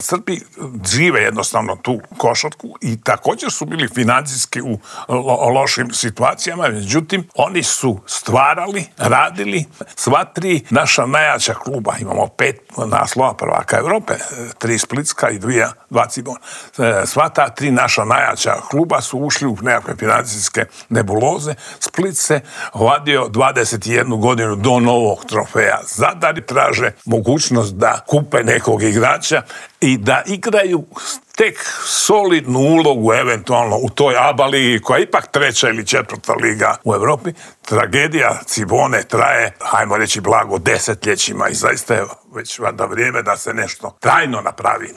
Kako sve krenulo prema dolje, pokušali smo odgonetnuti u Intervjuu tjedna Media servisa u kojem je gostovao bivši TV komentator, legendarni Slavko Cvitković.